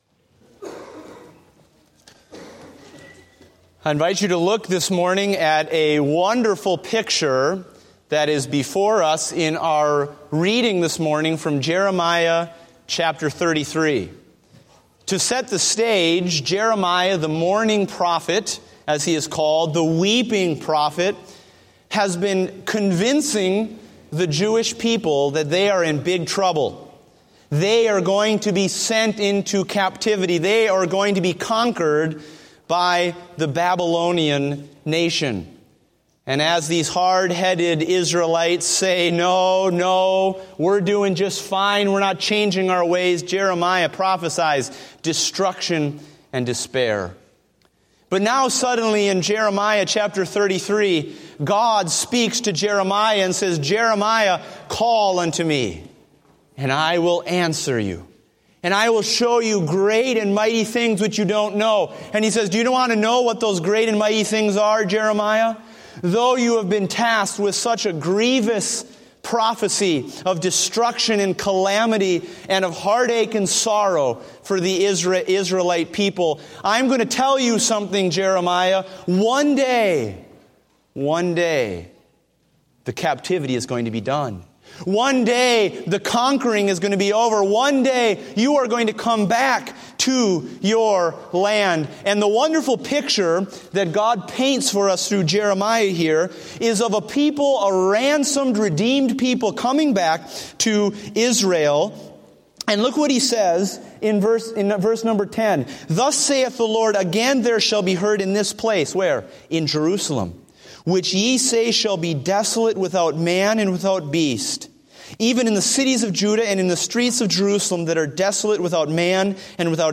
Date: November 8, 2015 (Morning Service)